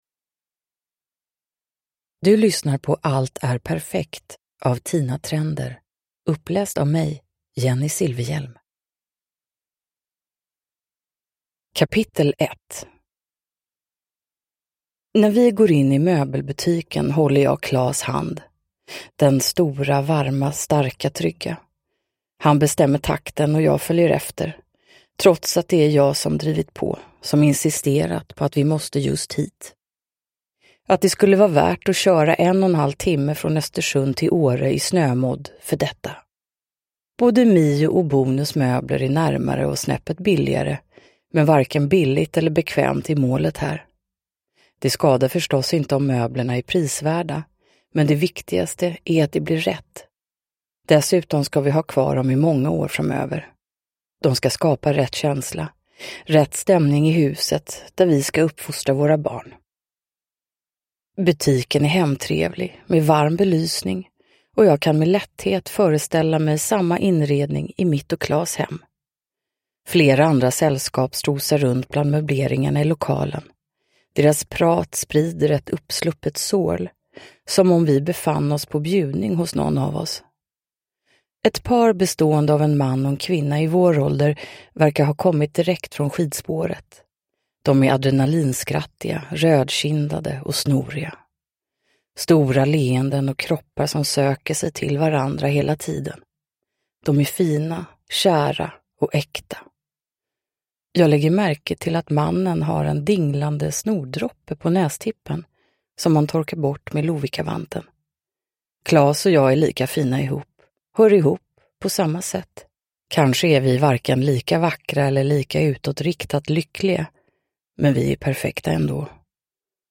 Uppläsare: Jennie Silfverhjelm
Ljudbok